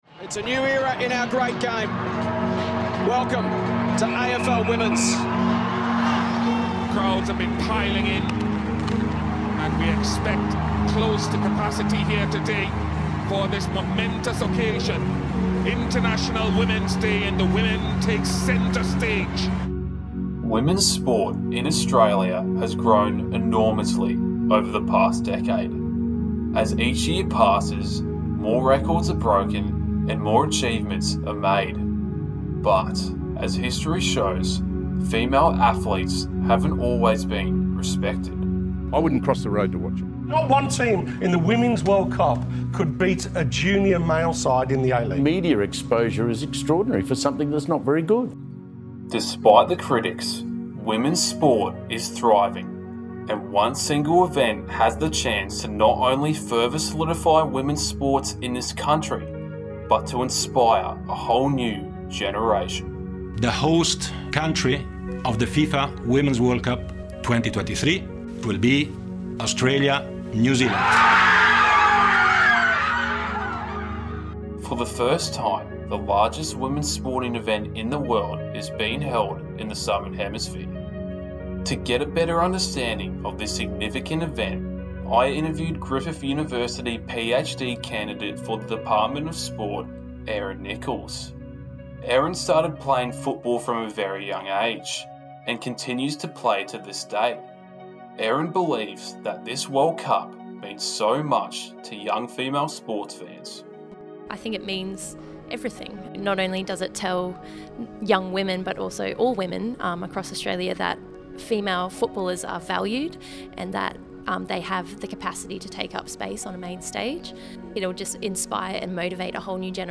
Vox Pops with members of the public interviewed at Brisbane Stadium on 27 July, 2023 at the Australia vs Nigeria match.